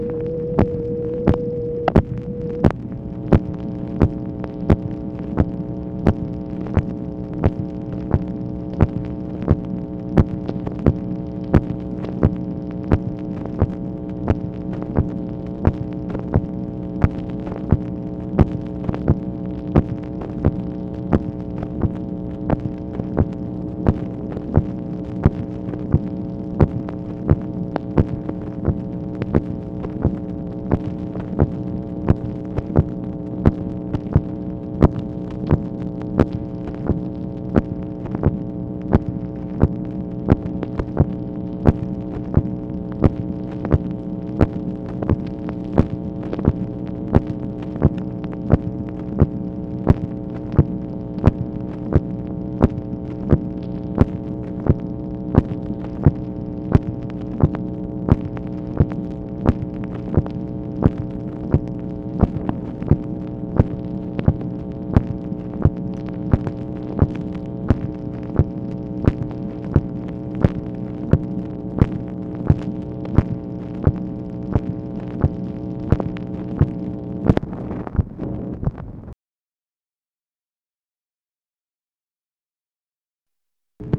MACHINE NOISE, October 20, 1964
Secret White House Tapes | Lyndon B. Johnson Presidency